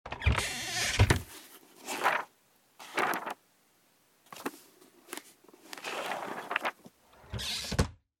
bookcase_2.ogg